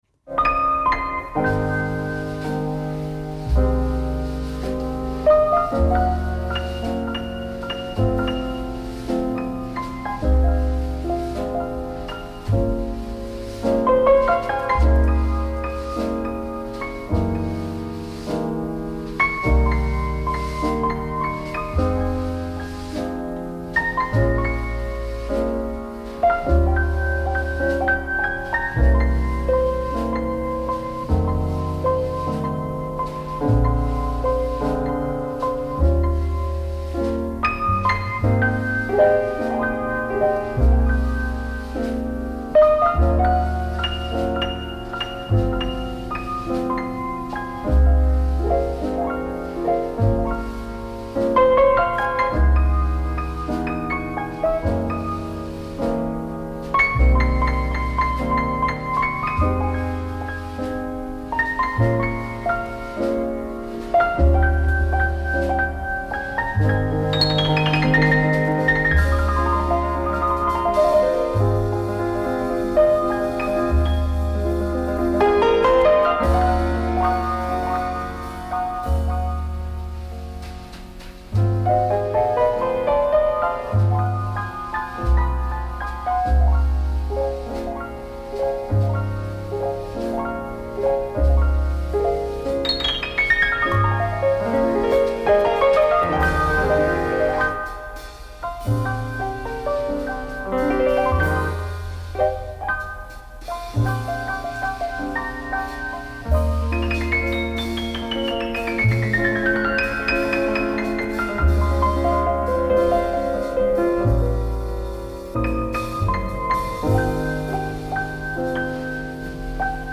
per una conversazione approfondita sul film e sulla figura di Garner.